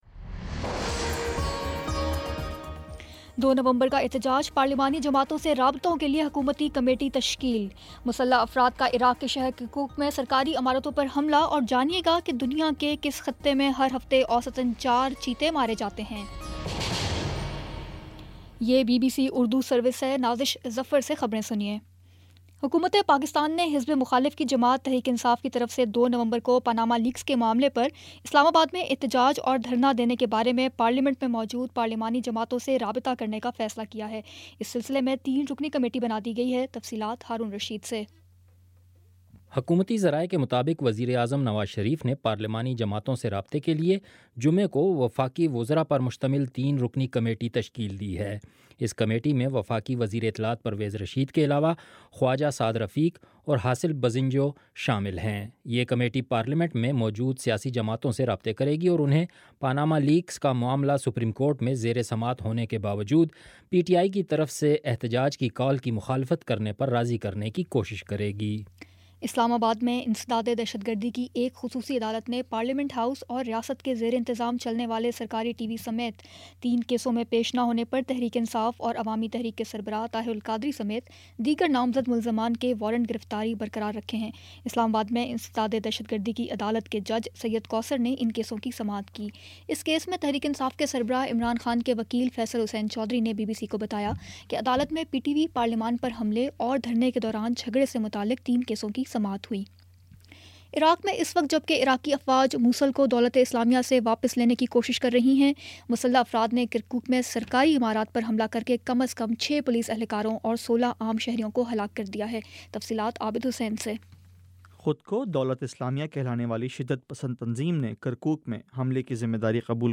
اکتوبر21 : شام سات بجے کا نیوز بُلیٹن